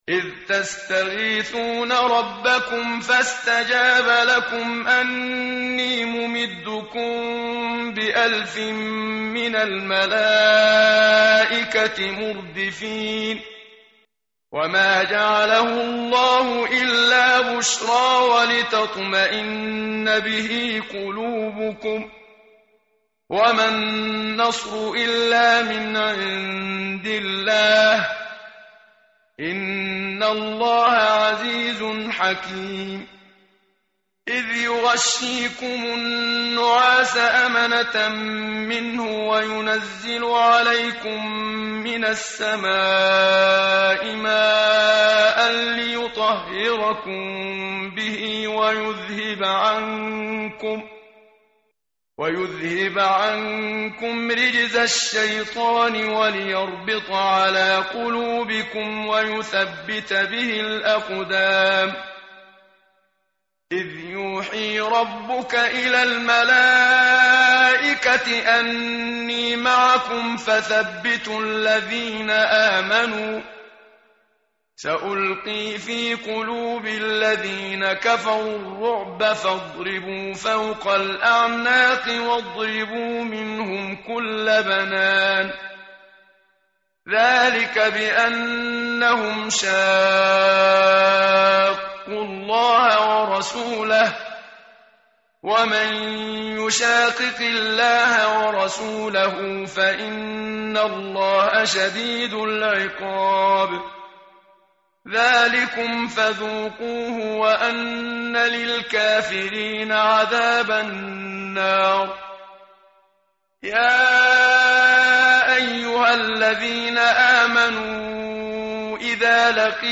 متن قرآن همراه باتلاوت قرآن و ترجمه
tartil_menshavi_page_178.mp3